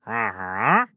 Minecraft Version Minecraft Version 1.21.4 Latest Release | Latest Snapshot 1.21.4 / assets / minecraft / sounds / mob / wandering_trader / idle3.ogg Compare With Compare With Latest Release | Latest Snapshot